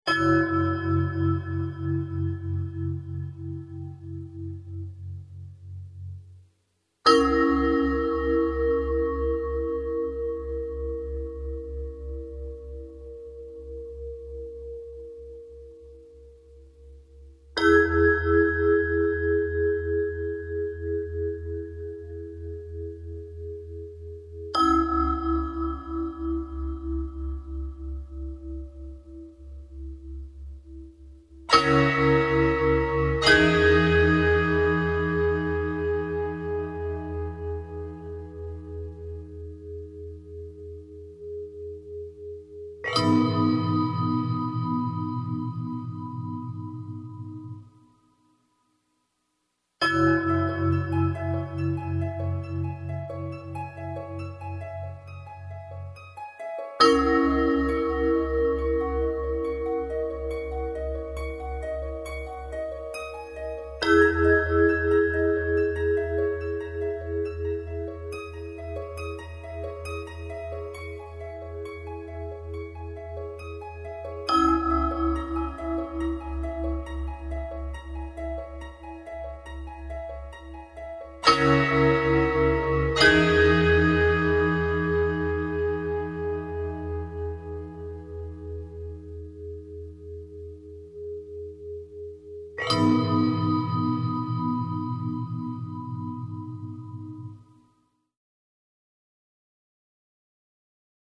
dark, lush and more than a little offbeat.